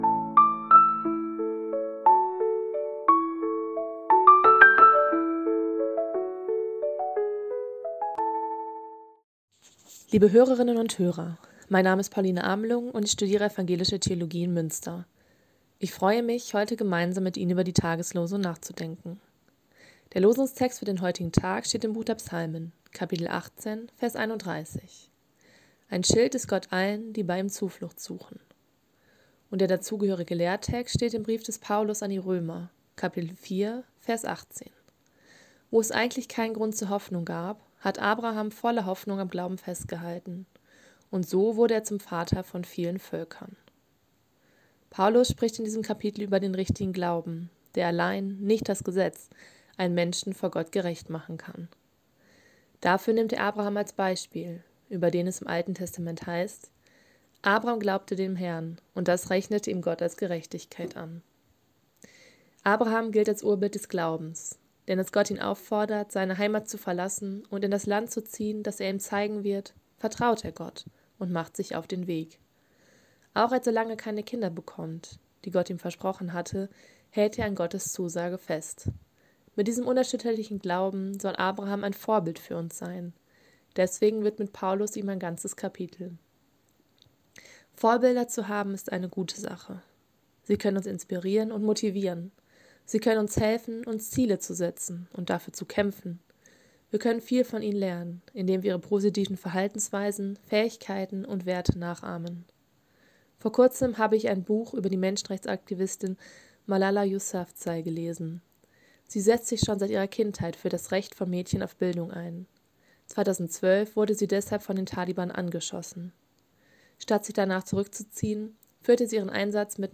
Losungsandacht für Dienstag, 18.11.2025